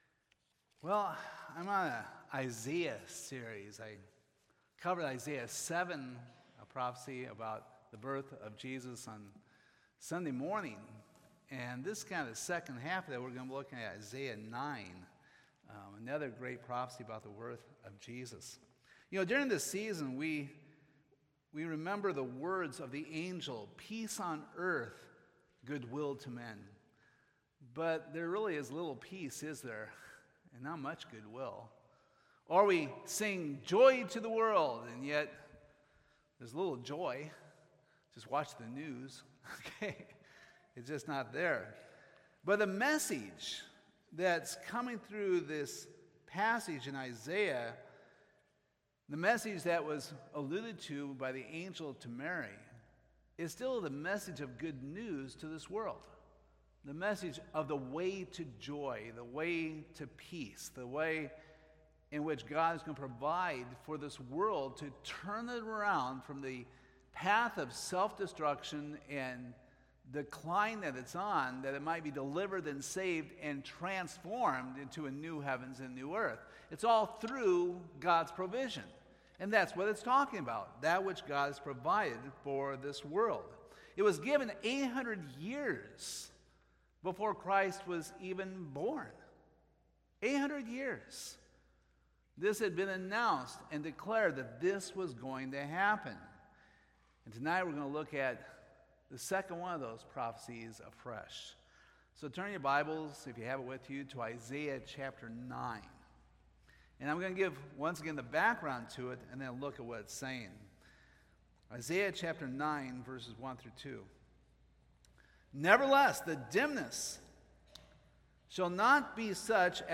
Bible Text: Isaiah 9:1-7 | Preacher
Christmas Eve